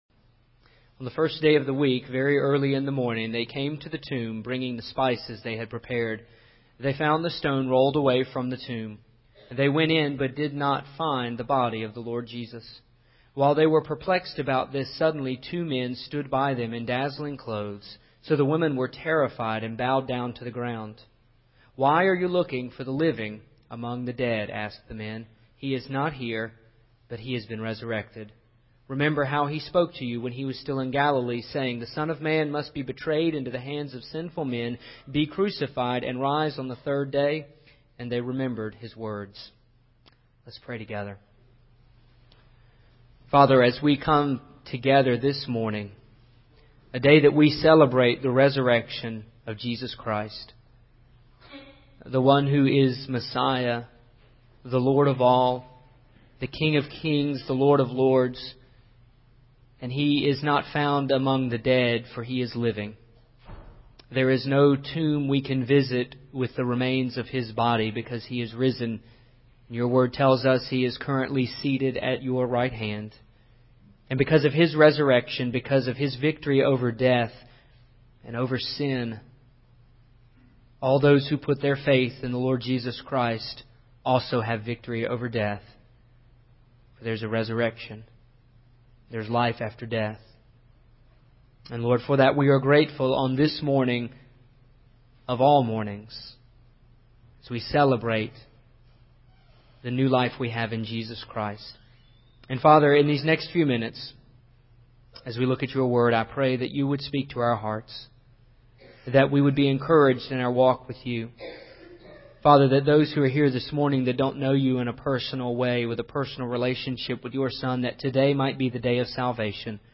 Here is part 4 of my sermon series “The Bible in 5 Weeks” It covers the Life of Christ.
The same way you preach through the Bible in 5 sermons…talk fast.